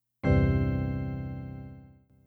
an unobtrusive, bland, scene separator.
Stingers.